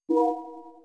send word keyboard.wav